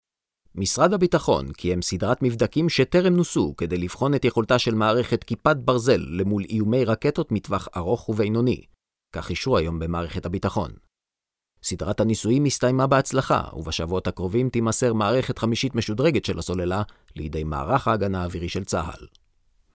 Hebrew. Actor, young, steady.
Hebrew accented English Corporate